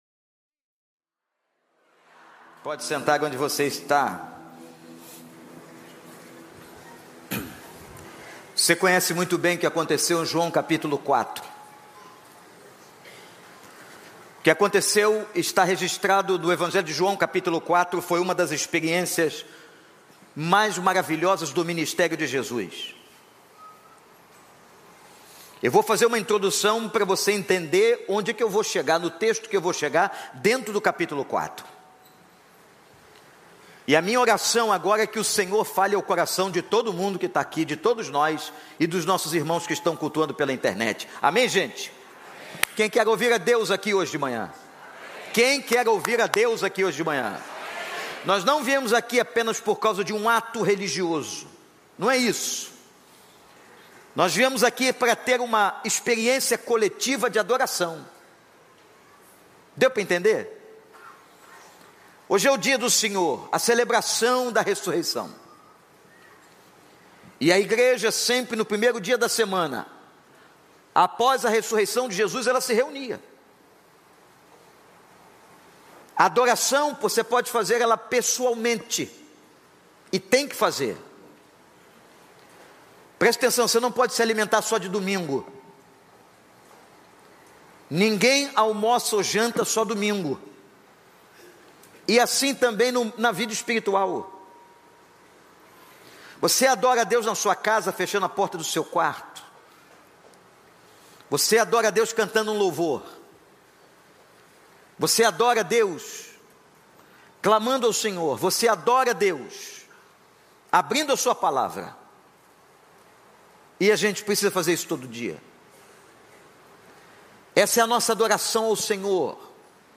Igreja Batista do Recreio